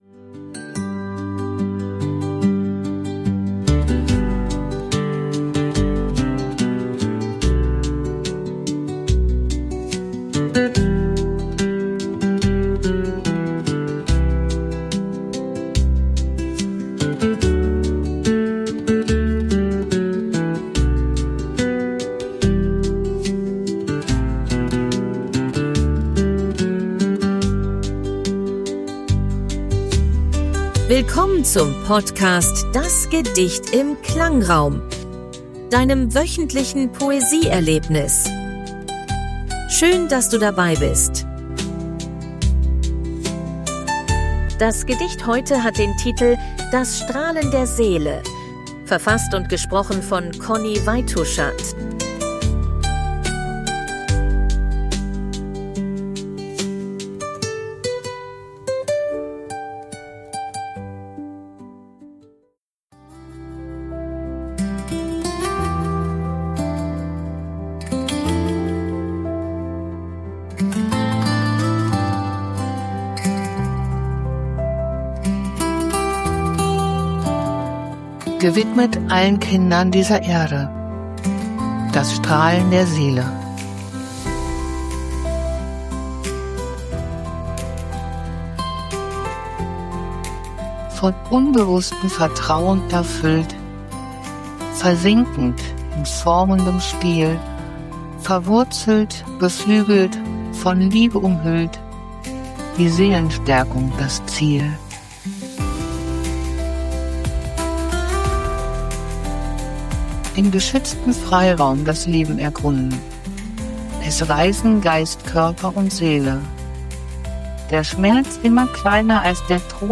KI-generierter Musik.